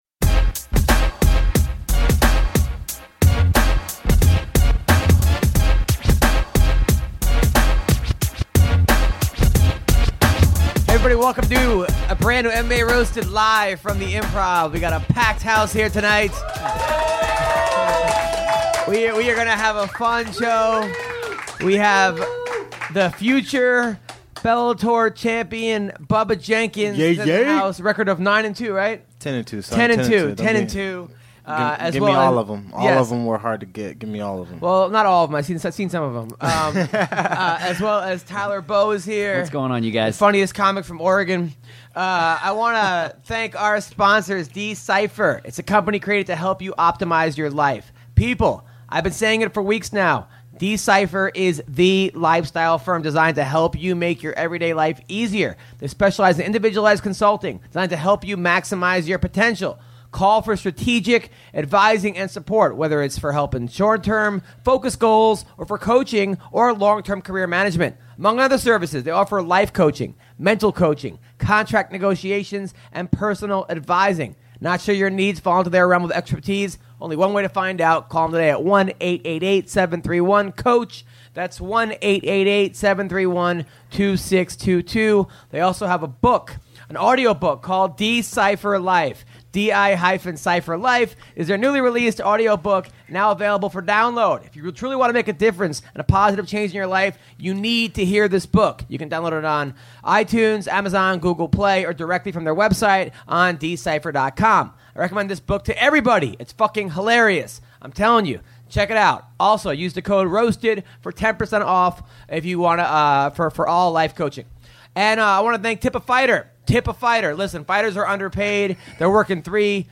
Live @ the Hollywood Improv